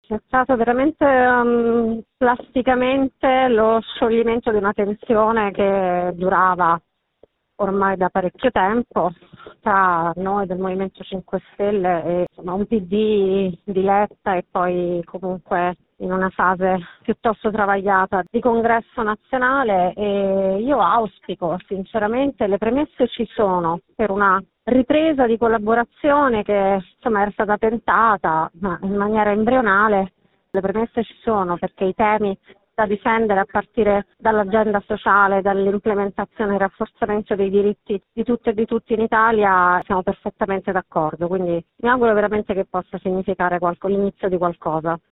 Noi abbiamo intervistato due esponenti di 5 Stelle e Pd. Cominciamo dalla senatrice pentastellata Alessandra Maiorino